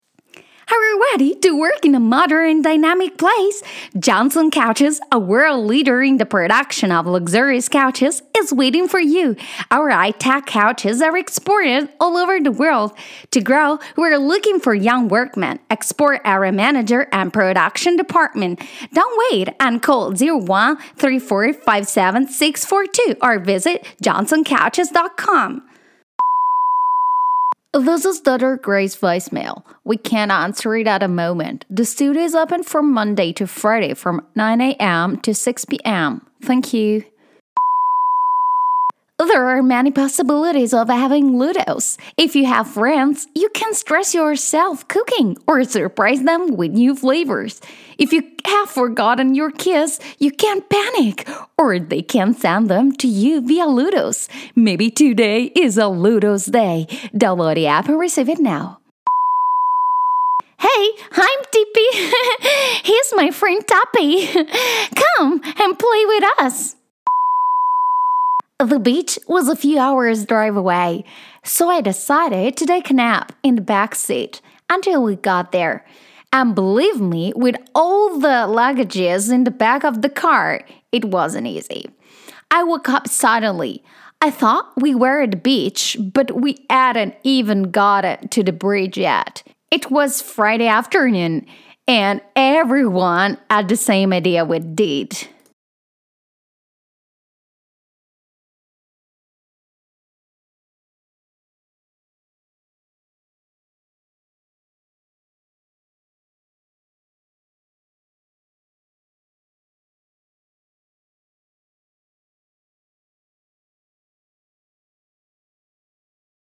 Young, Engaging, Deep, Cartoon, Narrator
English Demo
English - USA and Canada
Young Adult